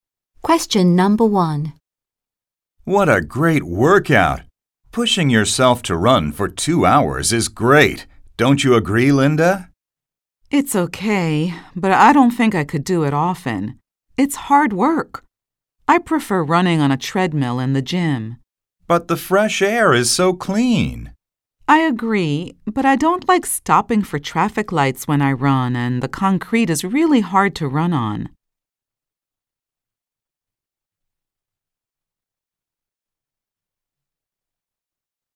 本試験に合わせ，一部イギリス英語も使用。